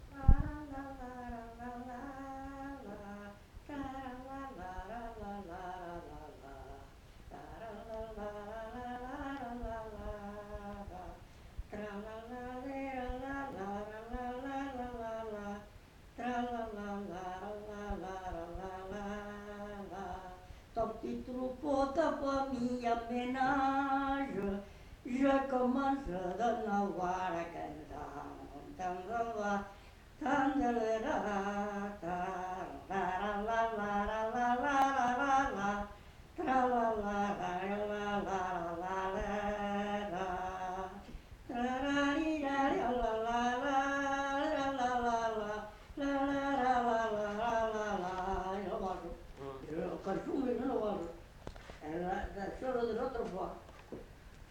Genre : chant
Effectif : 1
Type de voix : voix d'homme
Production du son : fredonné
Danse : valse